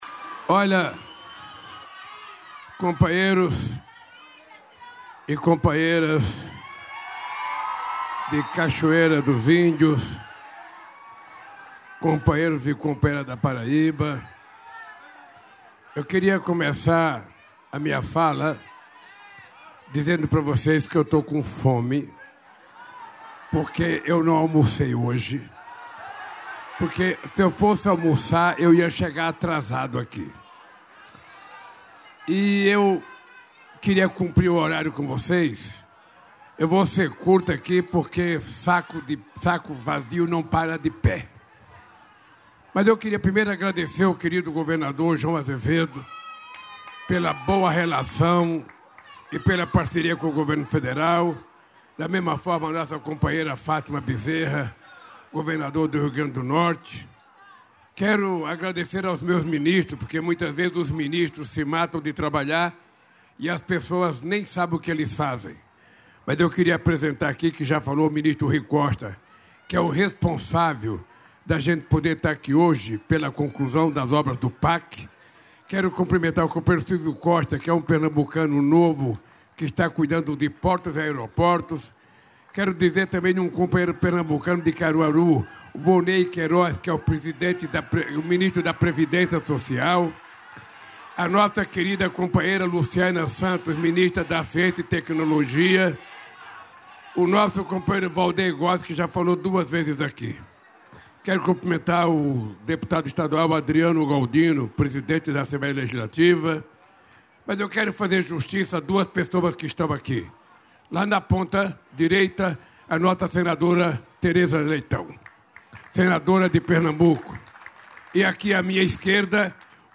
O presidente Luiz Inácio Lula da Silva participou, nesta quinta-feira (29), da cerimônia de entregas do Programa Terra da Gente, em Ortigueira (PR).
Durante o evento também foram anunciados investimentos ligados à agricultura familiar e extensão rural. Ouça a íntegra do discurso do presidente.